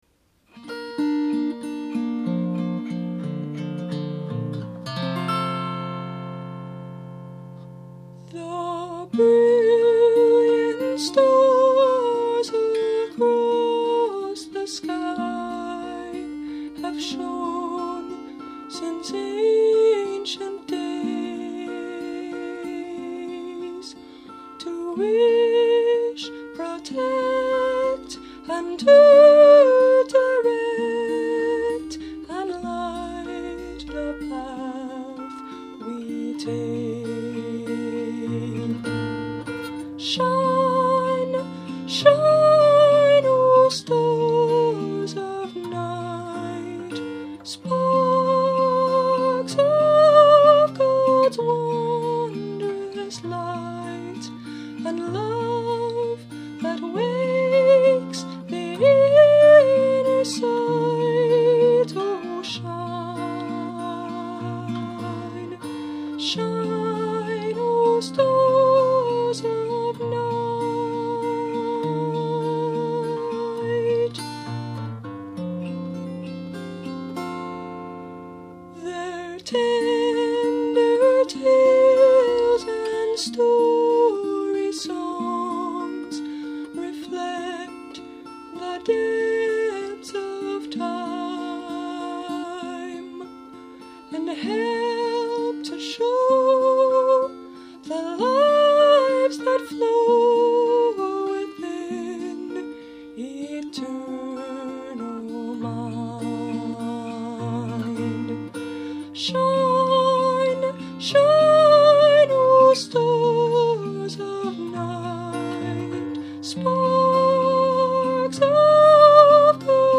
Instrument: Tempo – Seagull Excursion Folk Acoustic Guitar
(Capo 3)